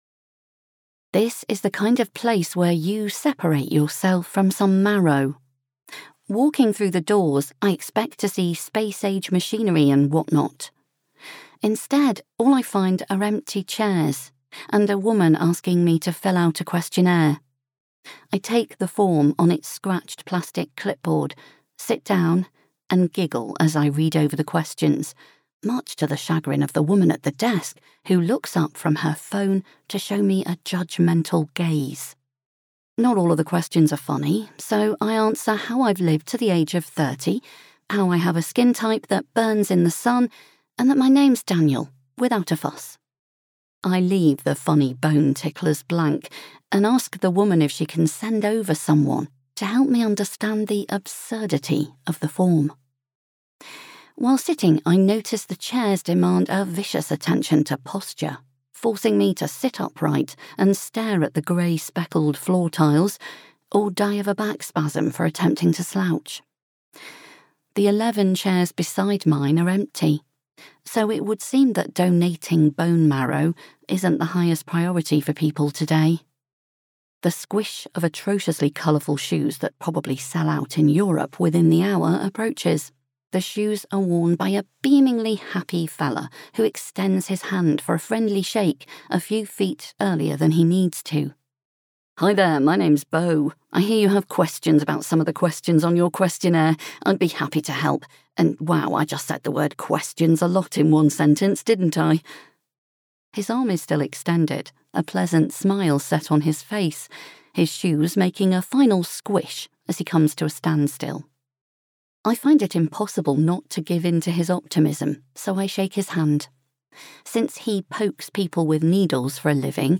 british, female